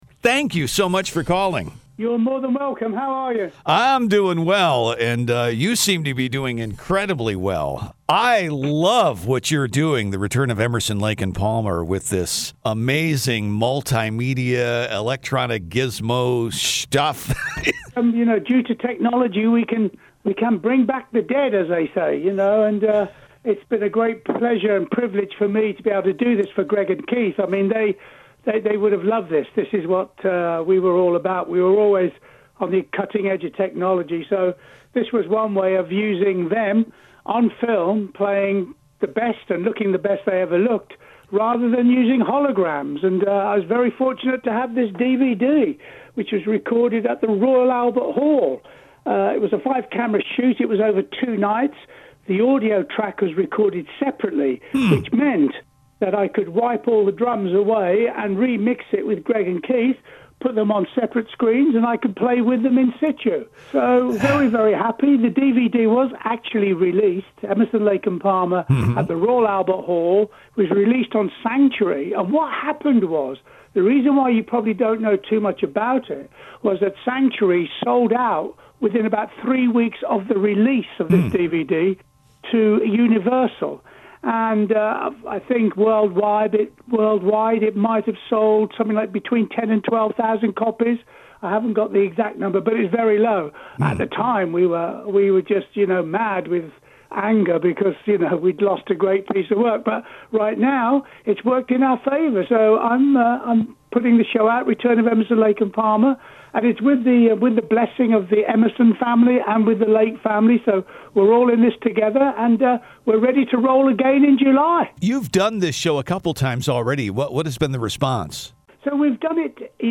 CARL PALMER INTERVIEW https